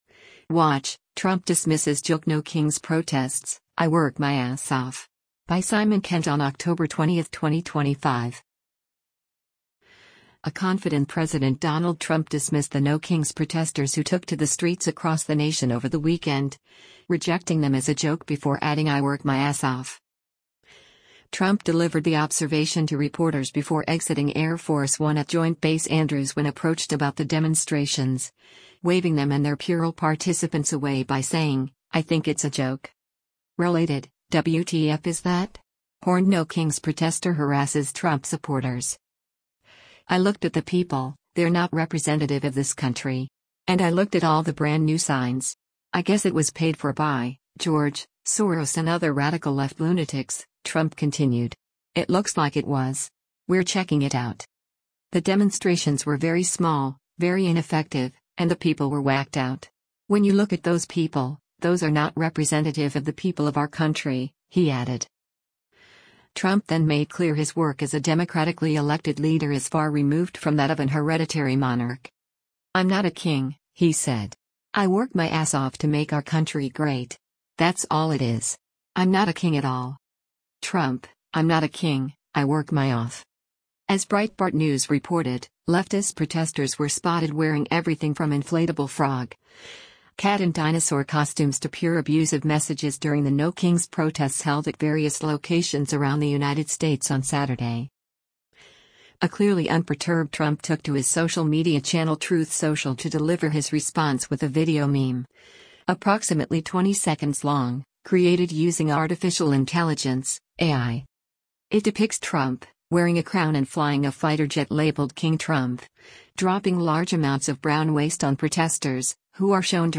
Trump delivered the observation to reporters before exiting Air Force One at Joint Base Andrews when approached about the demonstrations, waving them and their puerile participants away by saying, “I think it’s a joke.”